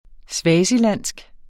swazilandsk adjektiv Bøjning -, -e Udtale [ ˈsvæːsiˌlanˀsg ] eller [ ˈswæːsi- ] Betydninger fra Swaziland (fra 2018 kaldet Eswatini), et land i det sydlige Afrika; vedr.